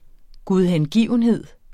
Udtale [ guðhεnˈgiˀvənˌheðˀ ]